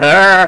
Single Laugh Sound Effect
Download a high-quality single laugh sound effect.
single-laugh.mp3